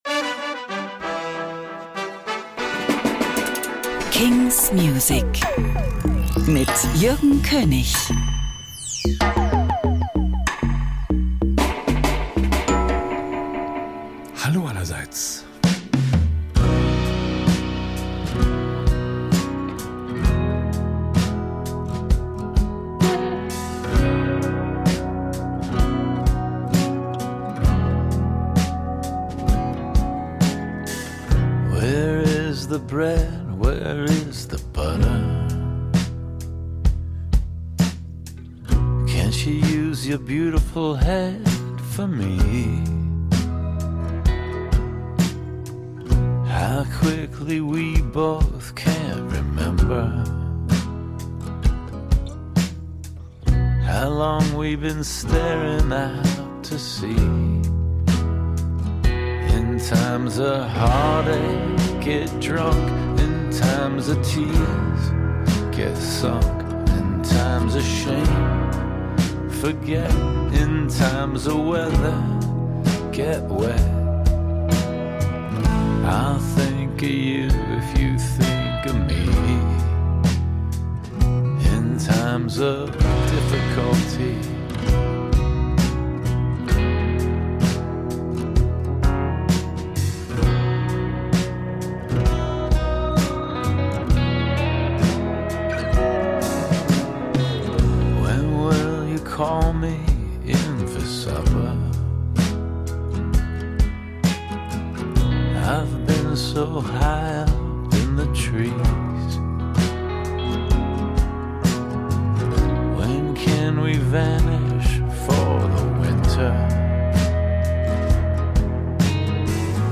indie & alternative releases